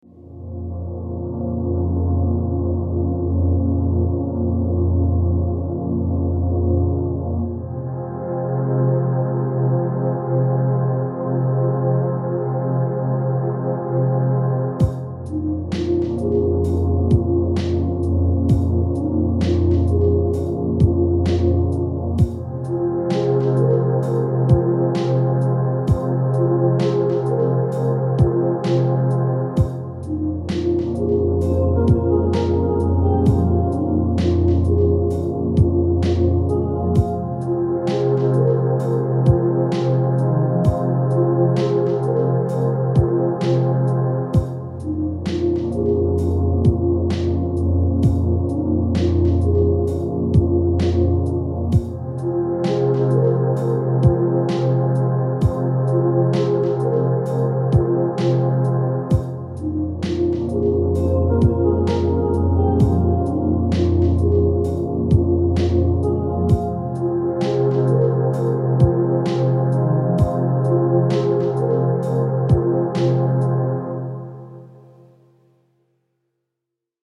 title screen music for my game.
it ends abruptly but it'll loop in-game.